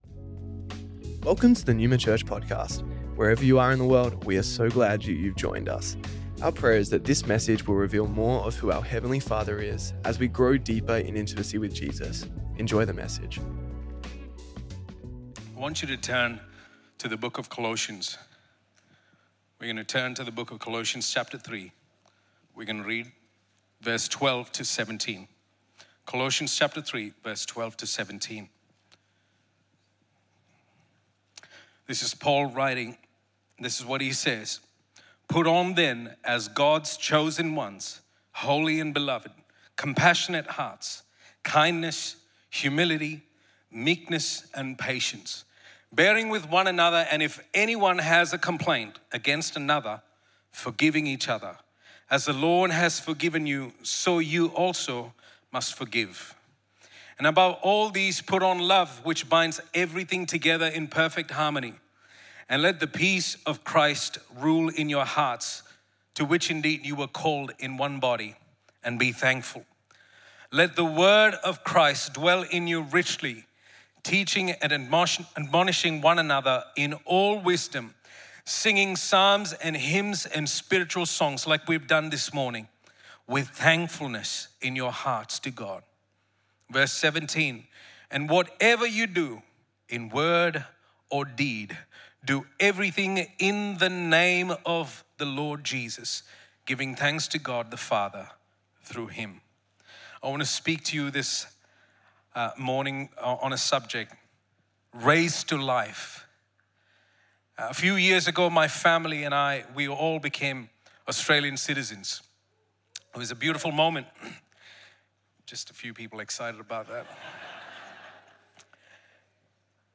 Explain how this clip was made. Resurrection Sunday Resurrection Sunday Originially recorded at Neuma Melbourne City on April 5th, 2026